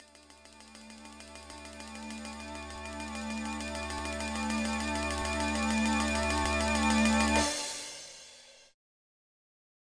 Countdown music